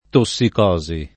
tossicosi [ to SS ik 0@ i ]